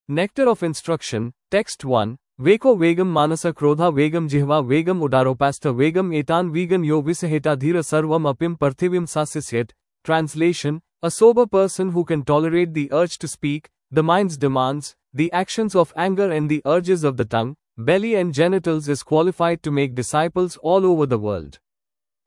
NOI-Text_AzureTTS.mp3